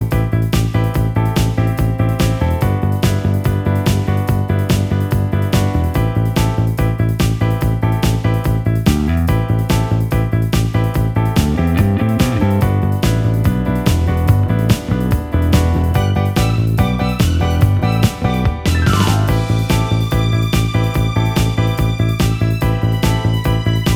Minus Guitars Pop (1980s) 3:35 Buy £1.50